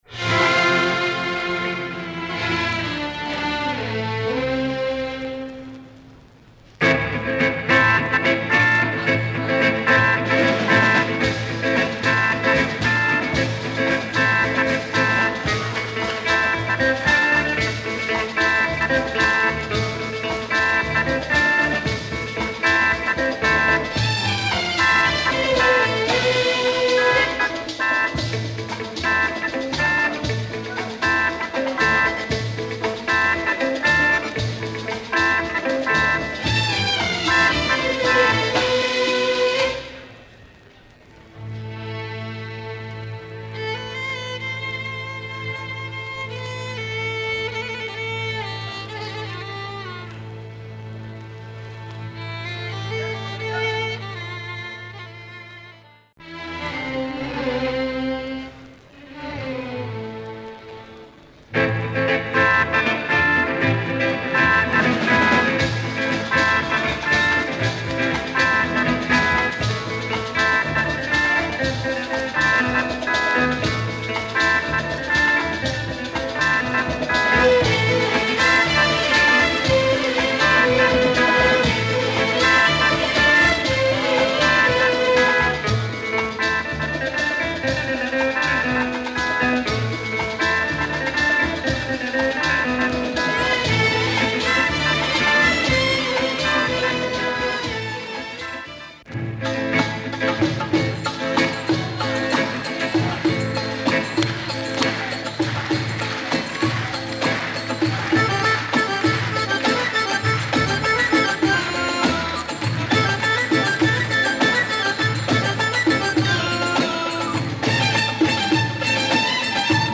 Wonderful oriental beats, great shape !